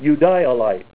Name Pronunciation: Eudialyte + Pronunciation Synonym: ICSD 68157 PDF 41-1465 Eudialyte Image Images: Eudialyte Natrolite Comments: Druse of gemmy, red eudialyte crystals to 1 mm on white natrolite.
EUDIALYT.WAV